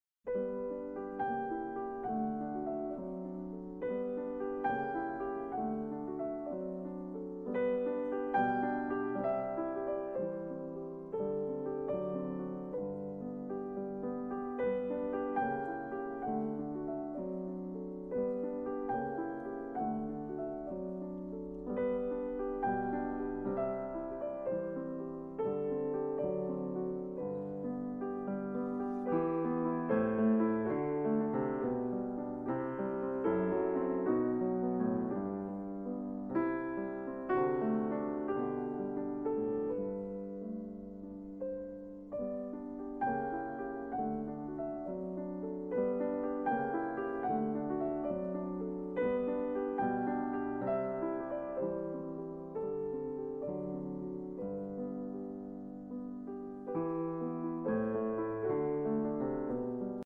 Robert Schumann - Kinderszenen op.15 Nr.1 Von fremden Landern und Menschen - Piano Music